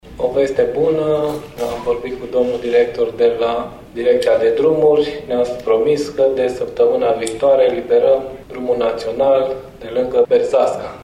Informația a fost confirmată de Direcția Regională de Drumuri şi Poduri Naţionale Timişoara şi de subprefectul judeţului Caraş-Severin, Sebastian Purec:
Subprefect-Sebastian-Purec-DN-57.mp3